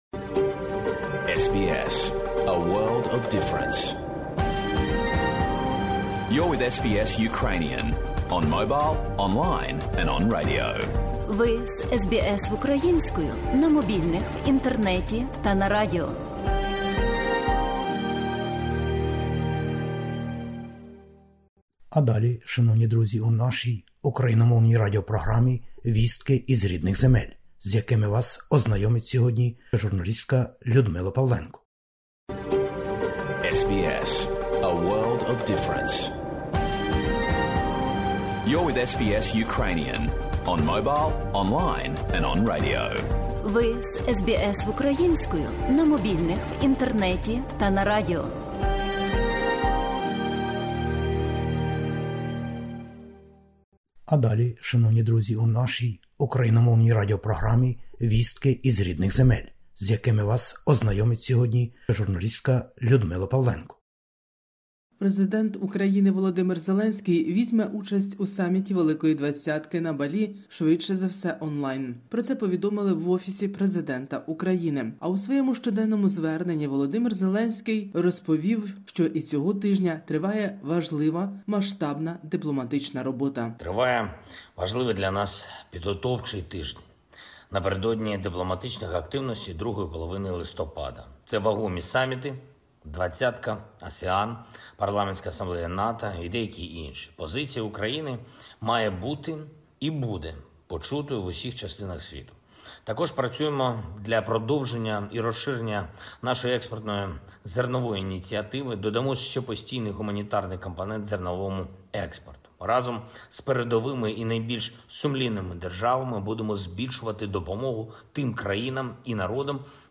Добірка новин із героїчної України